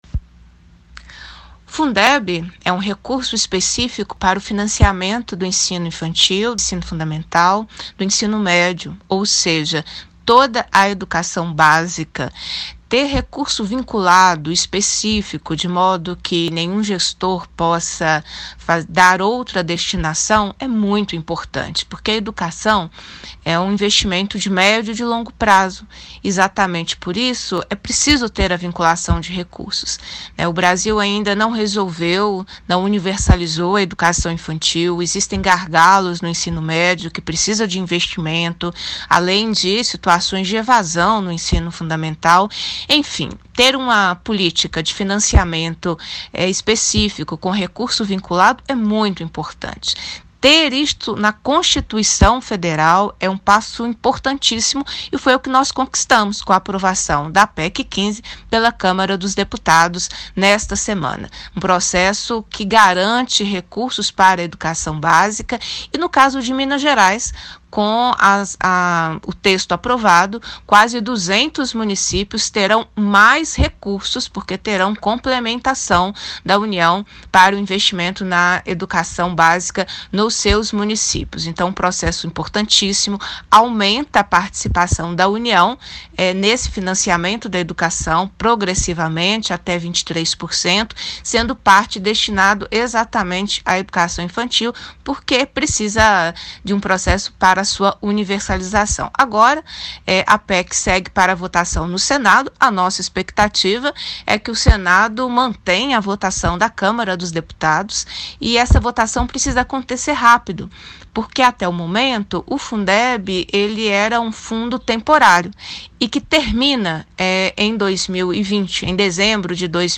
deputada-beatriz-cerqueira-fala-sobre-o-fundeb.mp3